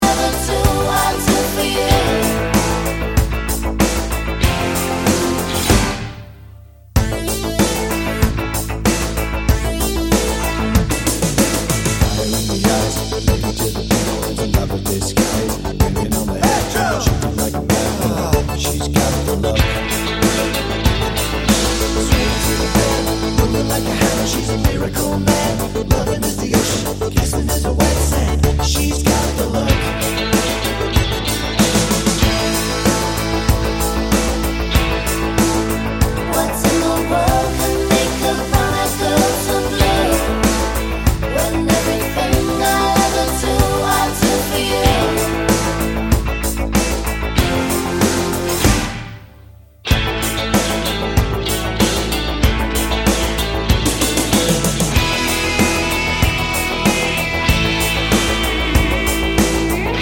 Duet Version Pop (1980s) 4:00 Buy £1.50